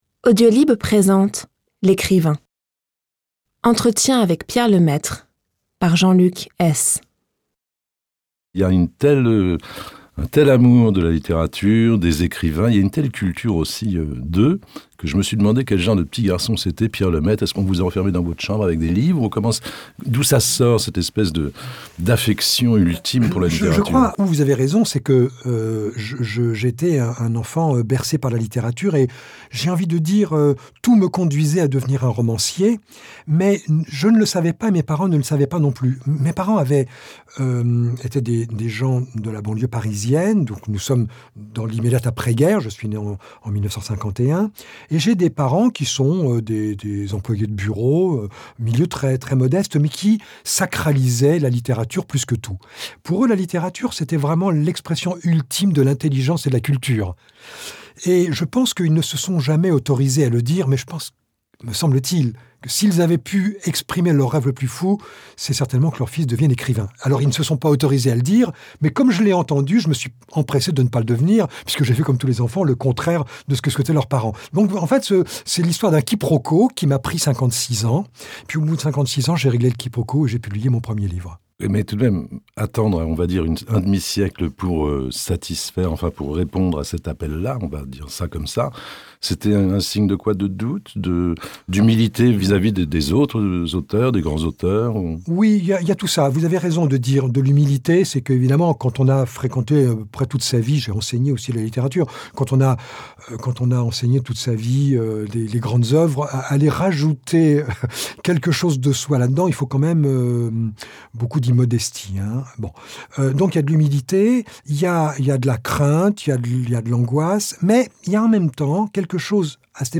L'Ecrivain - Pierre Lemaitre - Entretien inédit par Jean-Luc Hees